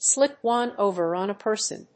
アクセントslíp óne òver on a person